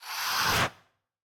Minecraft Version Minecraft Version snapshot Latest Release | Latest Snapshot snapshot / assets / minecraft / sounds / mob / breeze / inhale2.ogg Compare With Compare With Latest Release | Latest Snapshot
inhale2.ogg